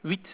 target sound
Tech. description: 8khz, 16 bit mono adpcm